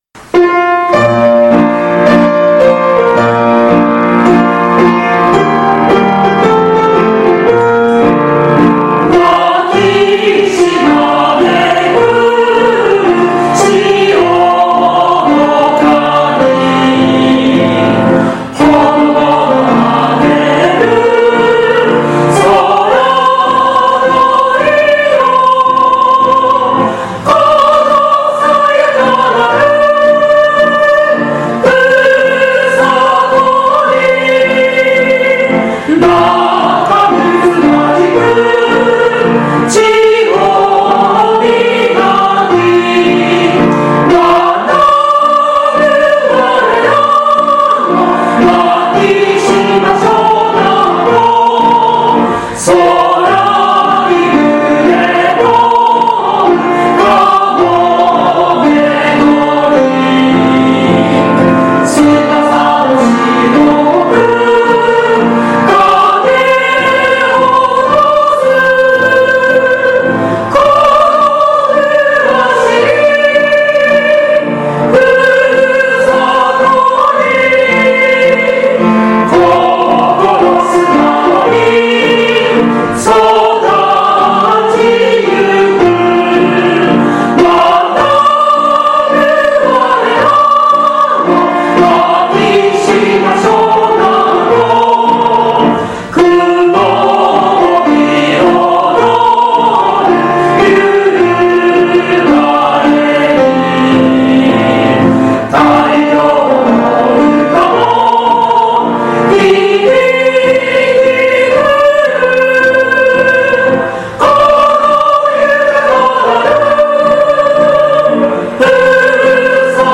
現行政区  旧行政区 No 小学校名  校歌楽譜・歌詞・概要  校歌音源（歌・伴奏） 　　備考
makisimasho_kouka_gattsho.mp3